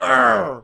Звуки гоблина
Здесь вы найдете рычание, скрежет, зловещий смех и другие устрашающие эффекты в высоком качестве.
Гоблину очень больно